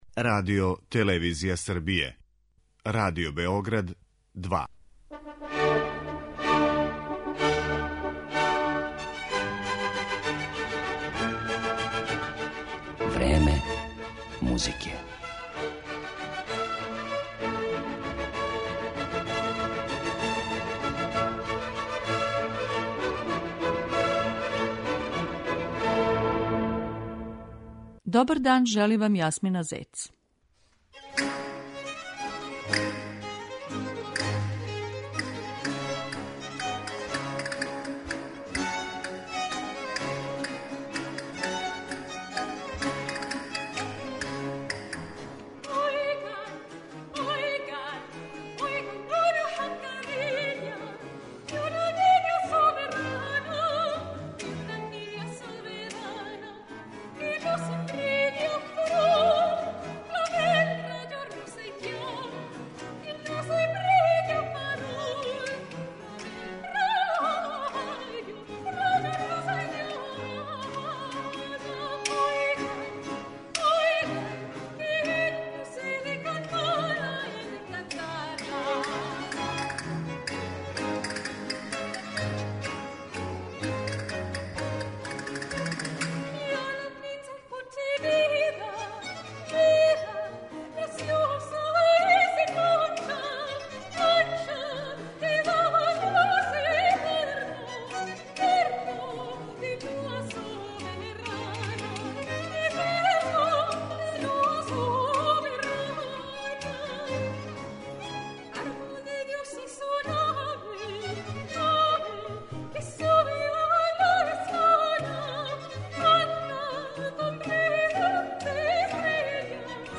Слушаћете дела шпанских композитора из 16,17. и 18. века
вокално-инструмeнтални ансамбл